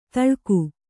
♪ taḷku